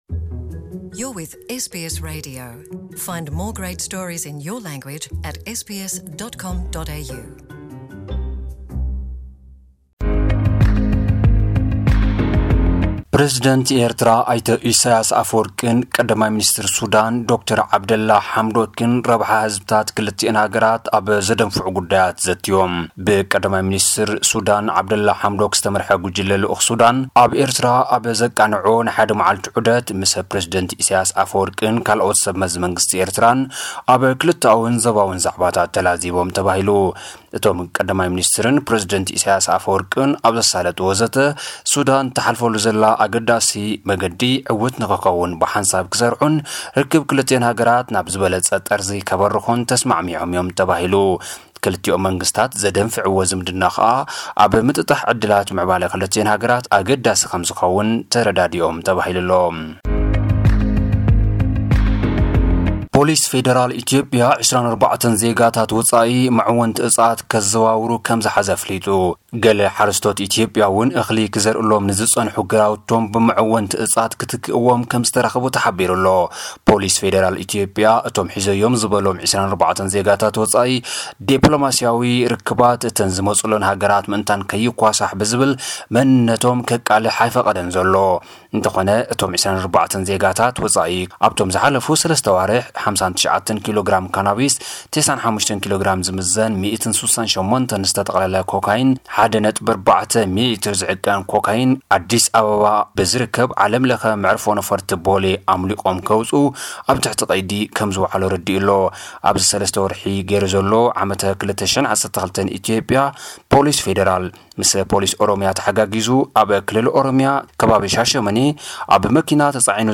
ጸብጻባት ዜናታት (28/11/2018) * ኢትዮጵያ መዐወንቲ ዕጻት ዘዘዋውሩ 24 ዜጋታት ወጻኢ ከም ዝሓዘት ኣፍሊጣ። * ፕረዚደንት ኢሳያስ ኣፈወርቂን ቀዳማይ ሚንስትር ሱዳን ዓብደላ ሓምዶክን ህዝባዊ ርክባት ከደንፍዑ ተረዳዲኦም ተባሂሉ። * ኣብ ዞባ ሰሜናዊ ቀይሕ ባሕሪ ኣስታት 80 ሚልዮን ዝኣባላቱ ዕስለ ኣንበጣ ኣብ ትሕቲ ቁጽጽር ውዒሉ ተባሂሉ።